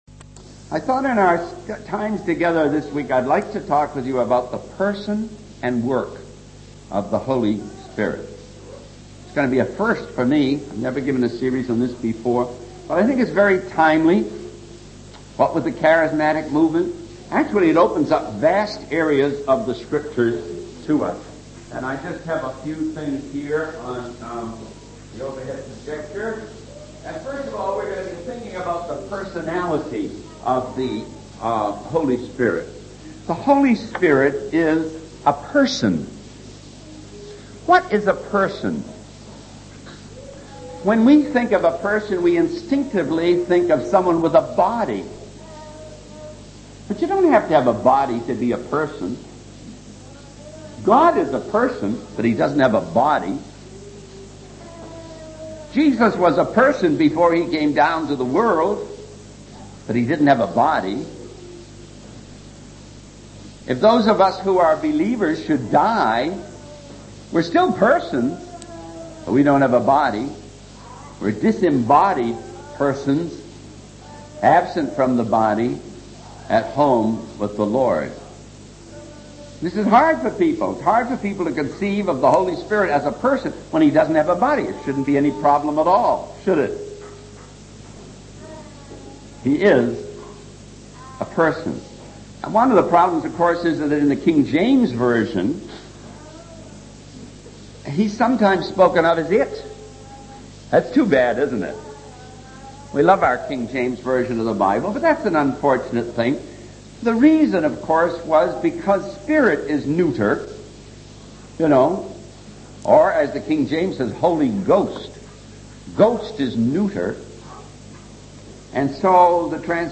In this sermon, the speaker addresses the frustration and confusion that arises when believers witness blessings and growth in churches that do not adhere to biblical truth. He emphasizes the importance of allowing the Holy Spirit to work in His own way and rejoicing in the glorification of Christ, even in challenging circumstances.